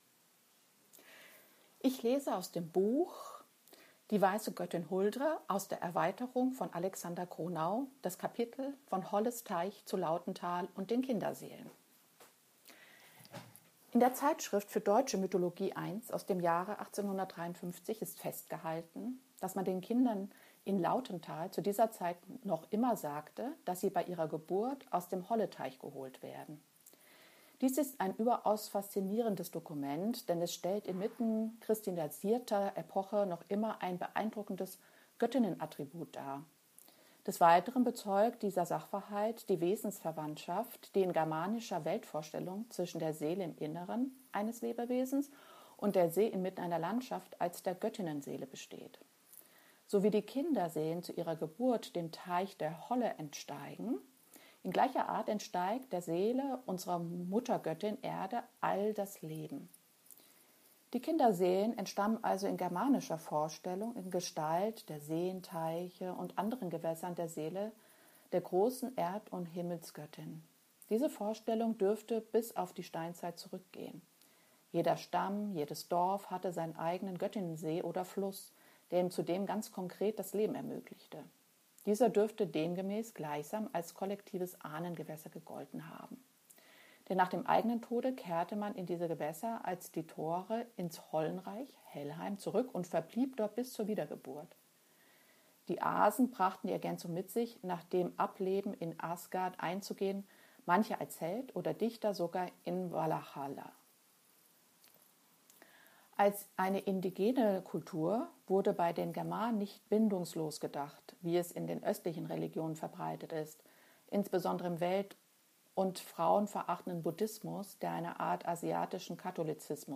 Weitere Lesungen